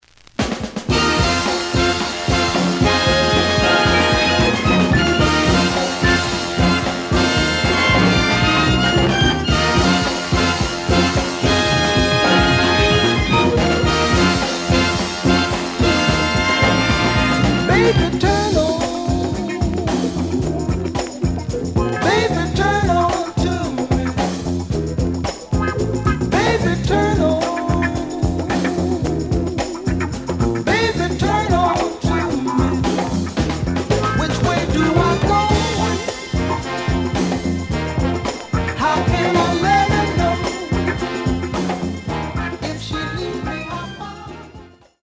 The audio sample is recorded from the actual item.
●Genre: Funk, 70's Funk